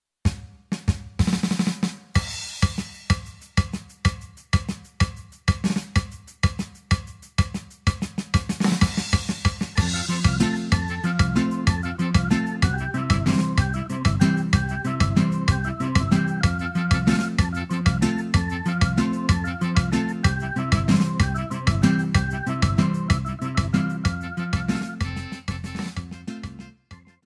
31-Zapateado.mp3